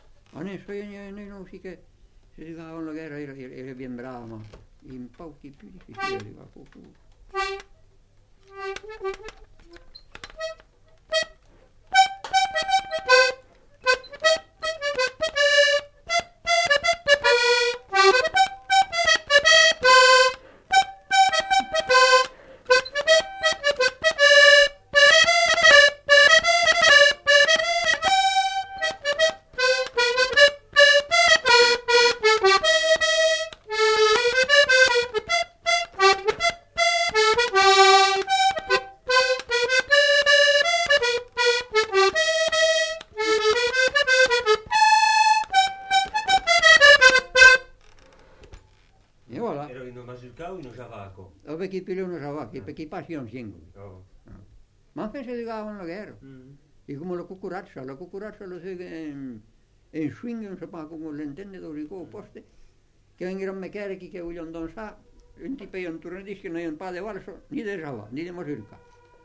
Genre : morceau instrumental
Instrument de musique : accordéon diatonique
Danse : java
Ecouter-voir : archives sonores en ligne